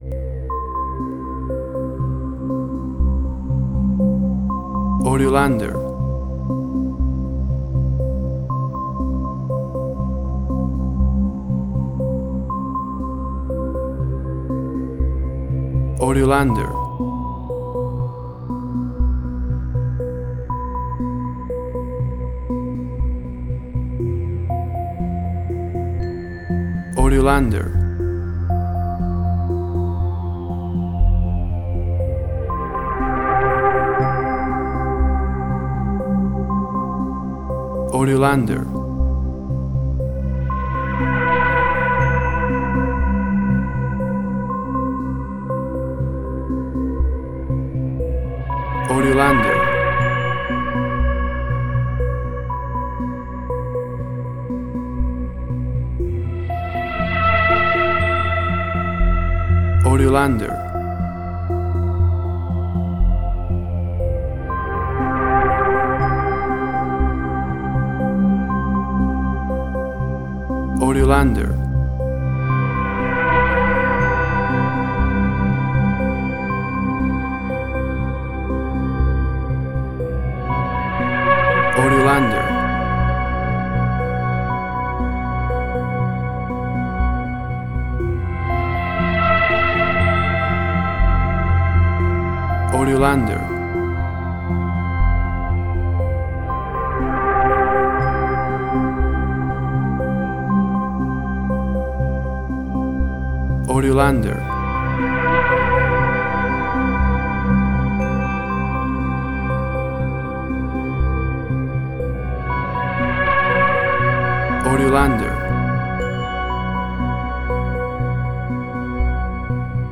Suspense, Drama, Quirky, Emotional.
Tempo (BPM): 60